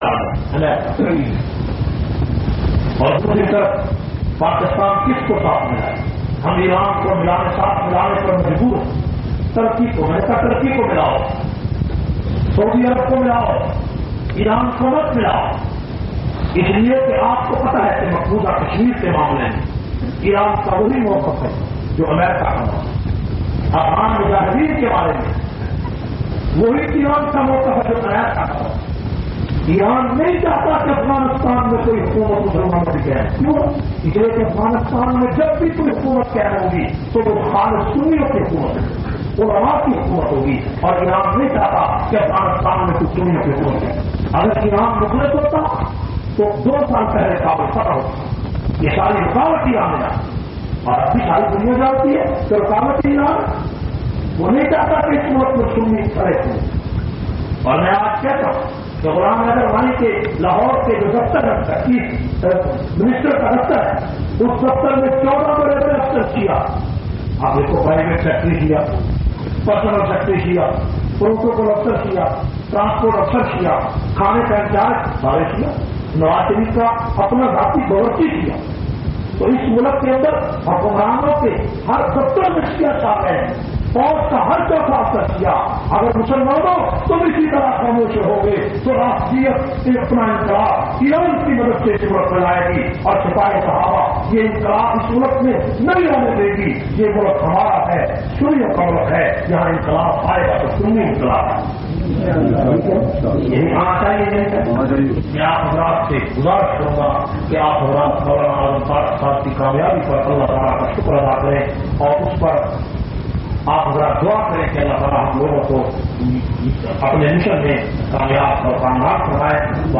529- Shan Risalat o Shan e Sahaba Jumma khutba Jamia Masjid Muhammadia Samandri Faisalabad.mp3